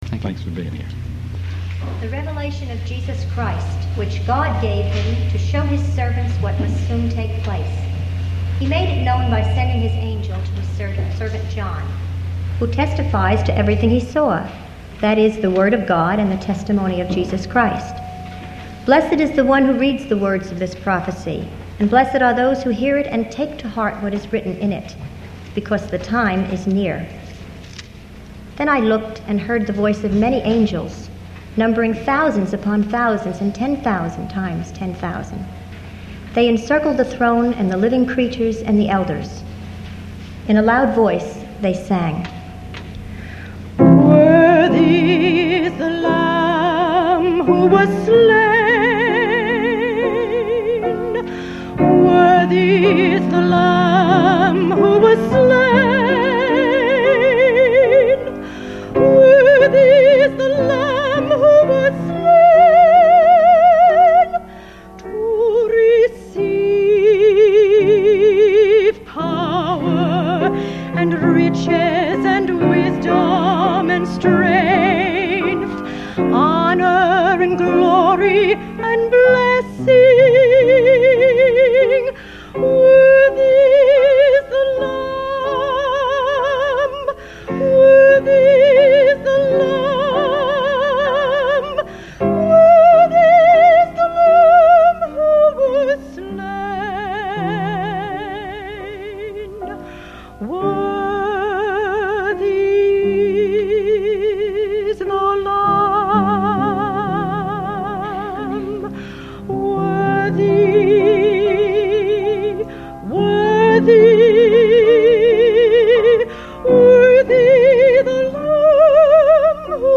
From Millington Baptist Church in Millington, New Jersey